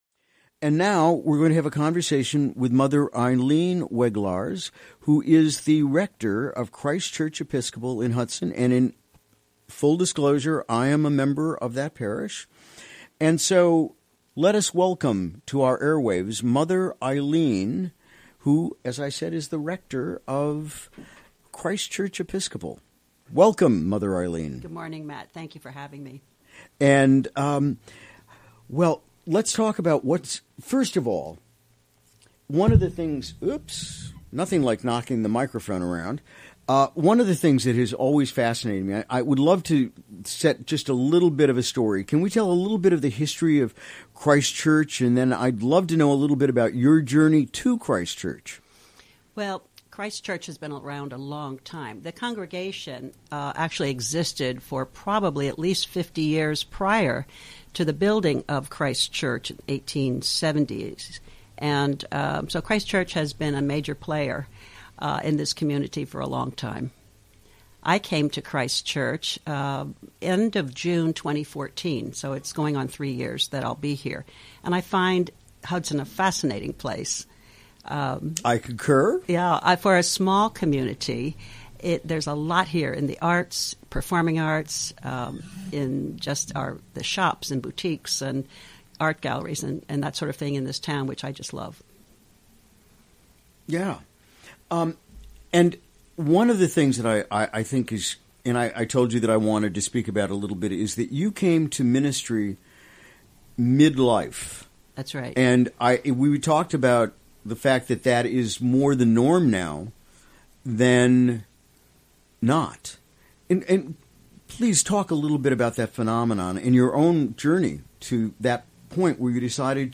Recorded during the WGXC Morning Show on Wednesday, May 10.